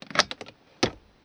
glove_compartment_opening.wav